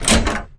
doorogg.mp3